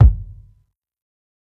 KICK_DEAD_ASS.wav